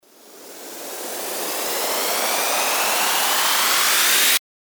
FX-1904-RISER
FX-1904-RISER.mp3